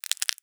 STEPS Glass, Walk 14, Light.wav